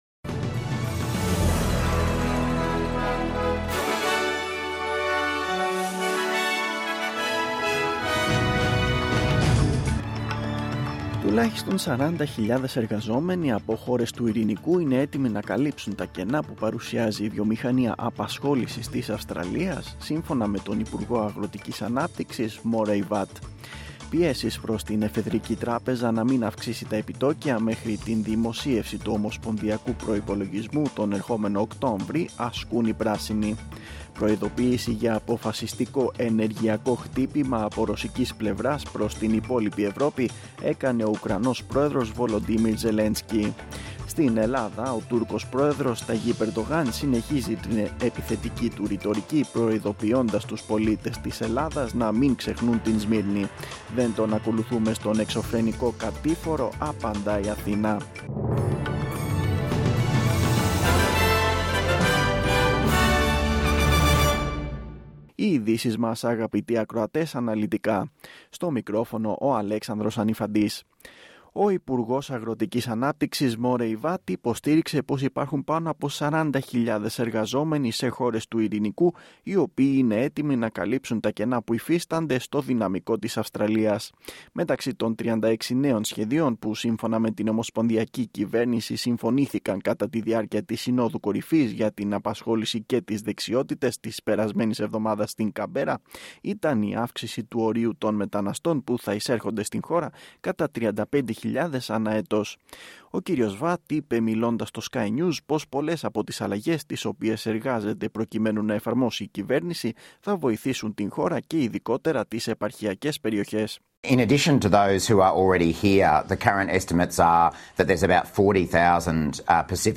Δελτίο Ειδήσεων: Κυριακή 4-9-2022
Το αναλυτικό δελτίο ειδήσεων του Ελληνικού Προγράμματος με ειδήσεις απ΄την Αυστραλία, την Ελλάδα και τον διεθνή χώρο.